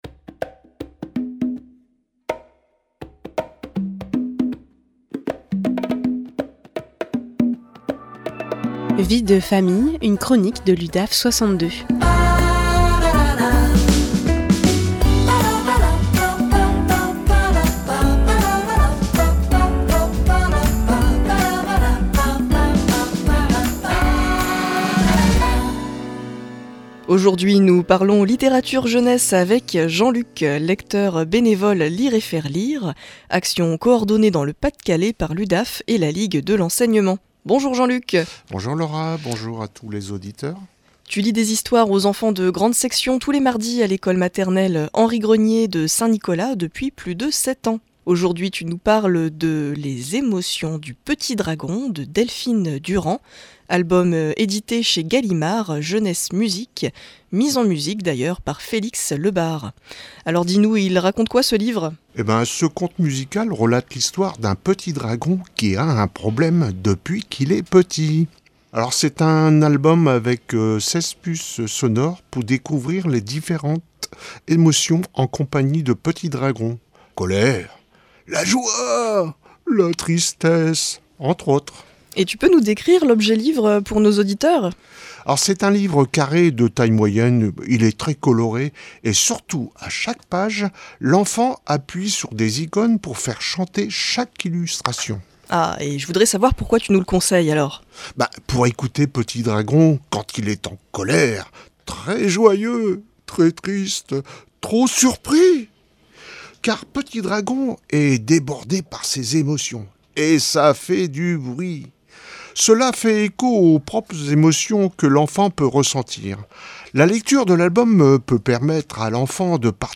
Vie de Famille, une chronique de l’Udaf62 en live sur RADIO PFM 99.9